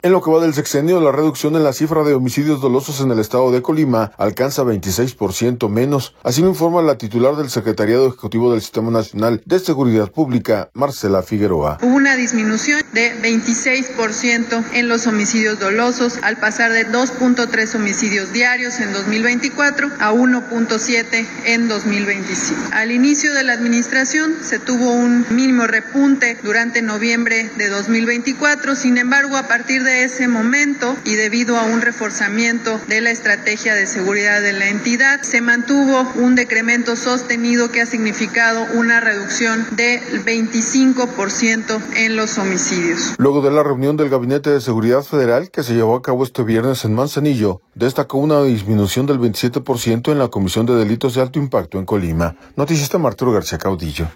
Luego de la reunión del Gabinete de Seguridad Federal que se llevó a cabo este viernes en Manzanillo, destacó una disminución del 27 por ciento en la comisión de delitos de alto impacto en Colima.